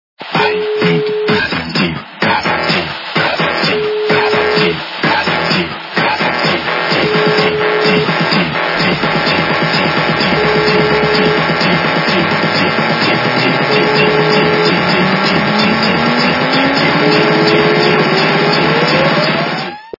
При заказе вы получаете реалтон без искажений.